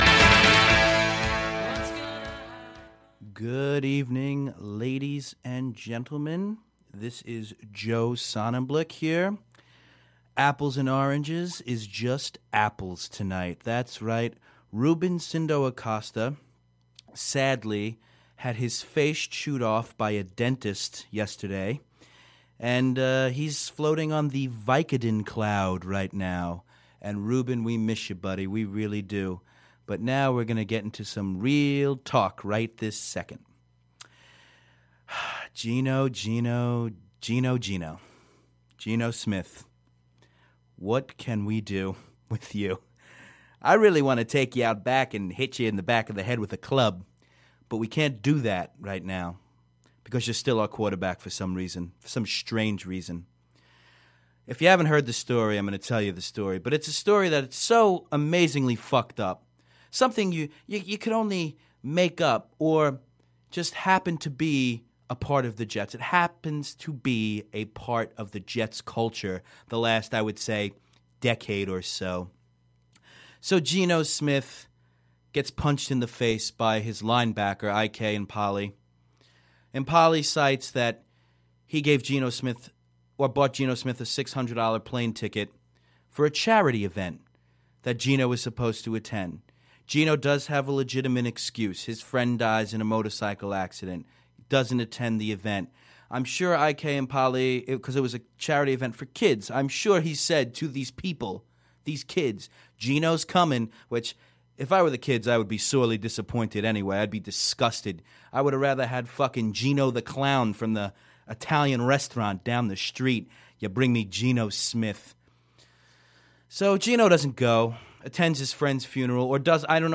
There are a lot of cuss words and pauses.